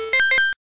correct ding sound-effect sound effect free sound royalty free Sound Effects